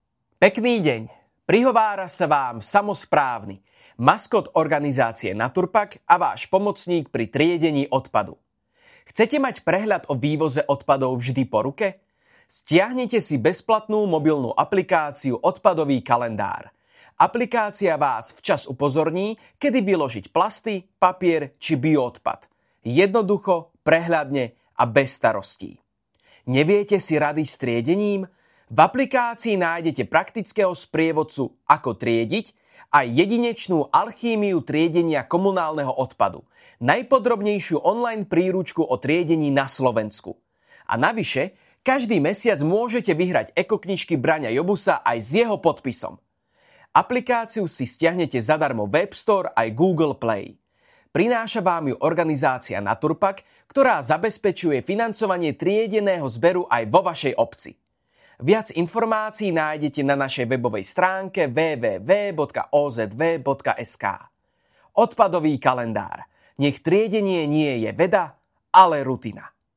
1. Rozhlasový spot – Verzia "Alchýmia triedenia" (dlhšia)
samo-spravny-odpadovy-kalendar-rozhlasovy-spot-1.mp3